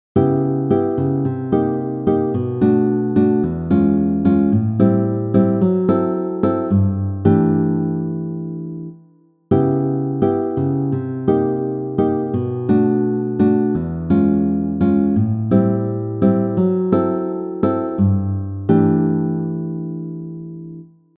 The chords in this last progression are C# minor 7 b5, C minor 7, B minor 7, E 7 (b9,13), A minor 7, F# minor 7 b5, and G Major 6 (9).
This is a typical jazz chord progression ending.
We’re using a Bossa Nova rhythm to switch things up here.
minor 7 b5 chord progression 3 guitar